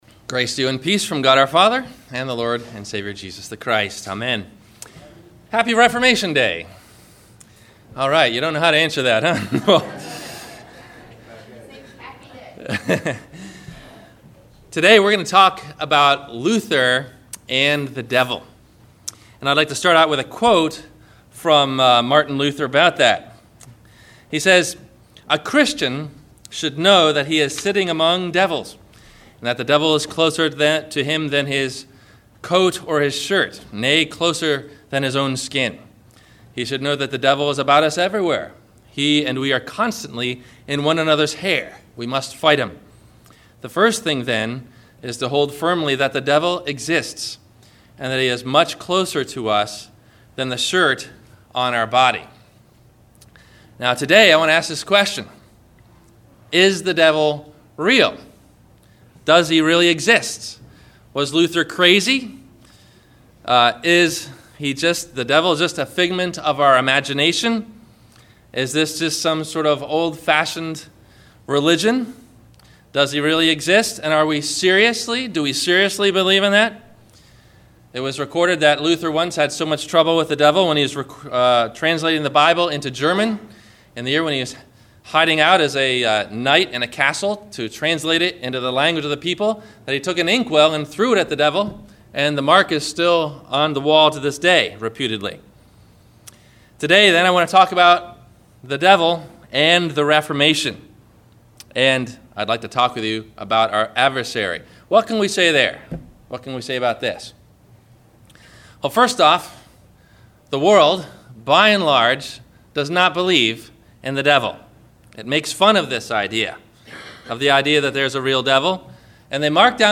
Is The Devil Real ? – Reformation Sunday – Sermon – October 30 2011